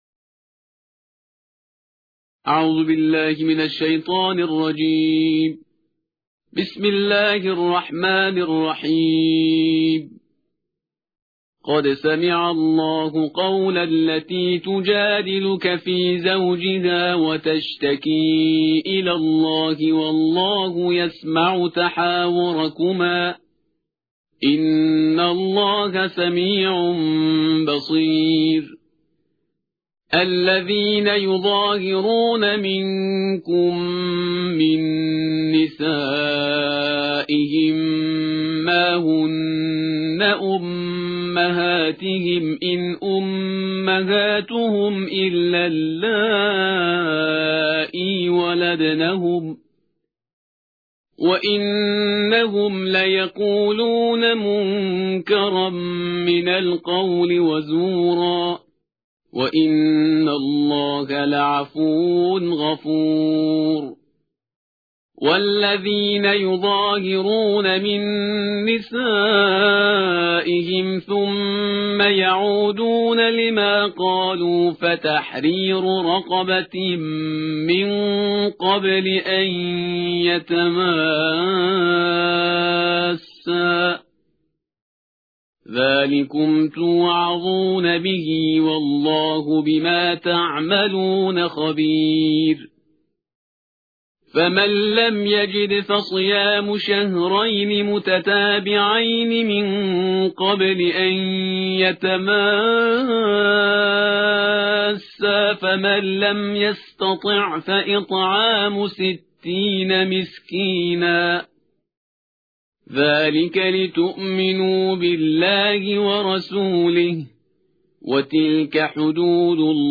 ترتیل جزءبیست و هشت قرآن کریم/استاد پرهیزگار